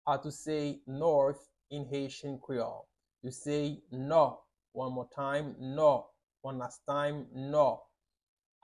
How to say "North" in Haitian Creole - "Nò" pronunciation by a native Haitian teacher
“Nò” Pronunciation in Haitian Creole by a native Haitian can be heard in the audio here or in the video below:
How-to-say-North-in-Haitian-Creole-No-pronunciation-by-a-native-Haitian-teacher.mp3